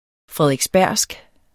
Udtale [ fʁεðʁεgsˈbæɐ̯ˀwsg ]